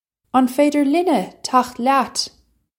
Pronunciation for how to say
Un faydger linna chakht lyat?
This is an approximate phonetic pronunciation of the phrase.